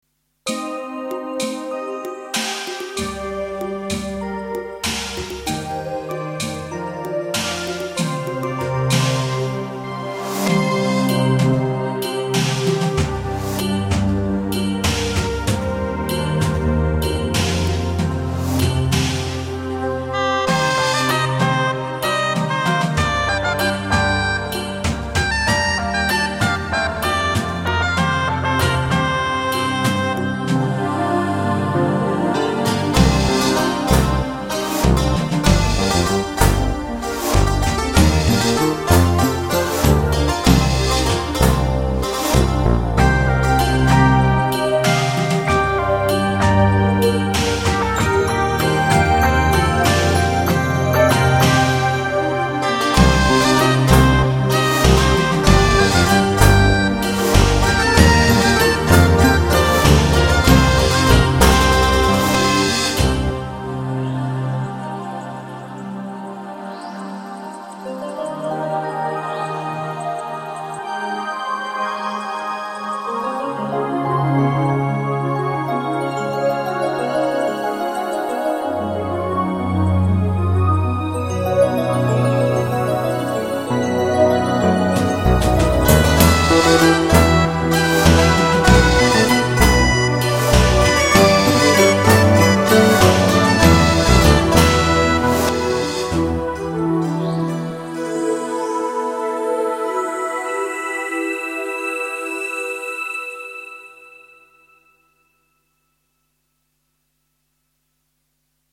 De folkloristische 'dulcimer' speelt hierin de hoofdrol temidden van elektronische koren en instrumenten.